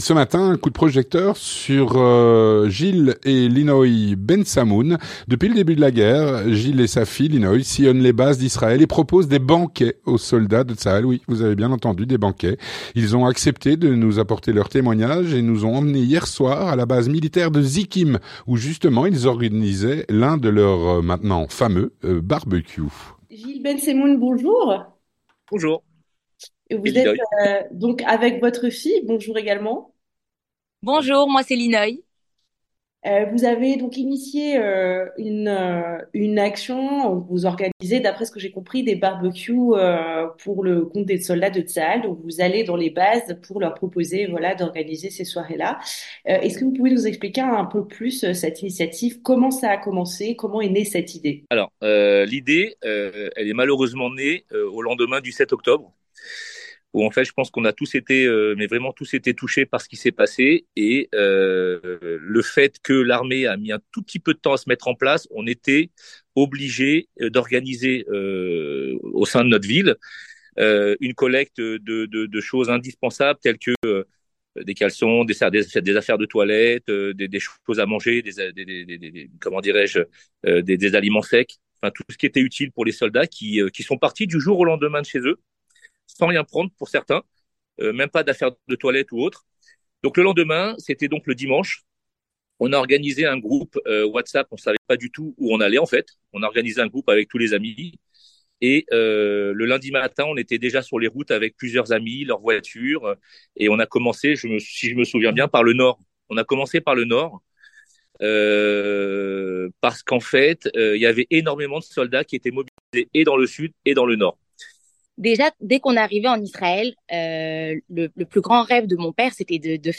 Ils nous ont emmenés hier soir à la base militaire de Zikim, où justement ils organisaient l'un de leur, maintenant, fameux barbecue.